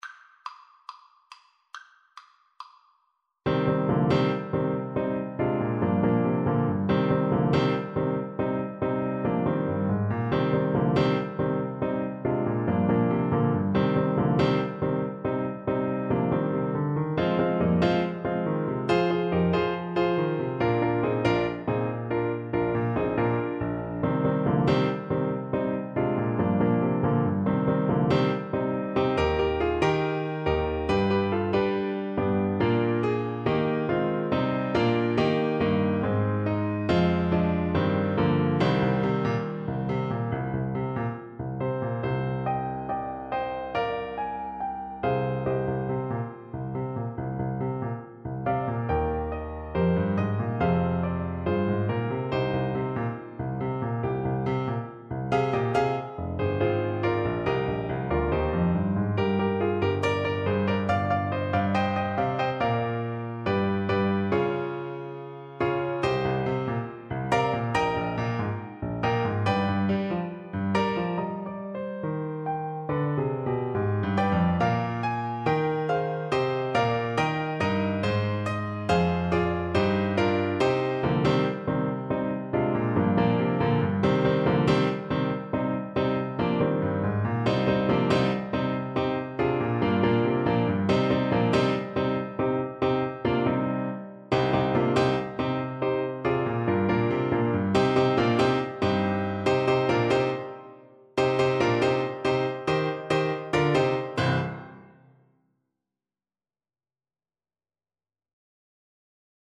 Alto Saxophone version
with a rock twist.
4/4 (View more 4/4 Music)
Driving forward = c. 140
Christmas (View more Christmas Saxophone Music)